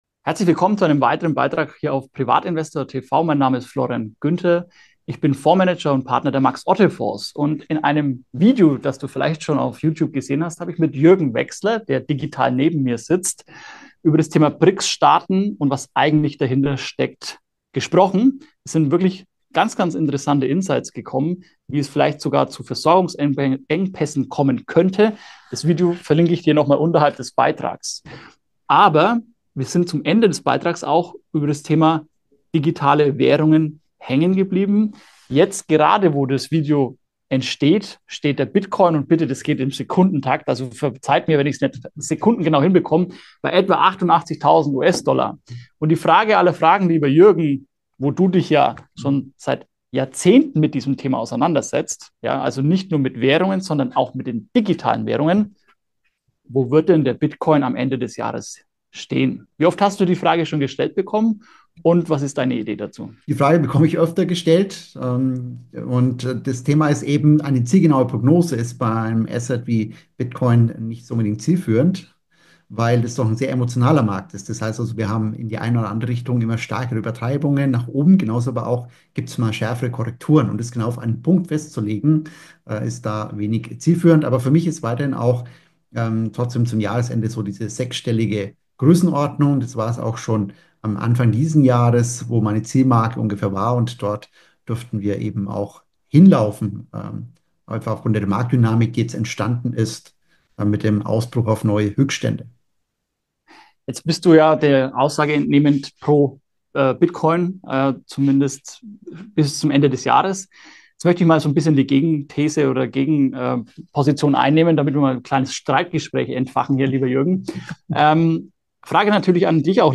Ein spannendes Gespräch
Ist die 21-Millionen-Grenze wirklich sicher? Hat die Wall Street die Kontrolle übernommen? Und wiederholen wir mit tausenden Kryptowährungen die Fehler des Mittelalters? Erfahren Sie die überraschenden Antworten in diesem aufschlussreichen Dialog zweier Experten.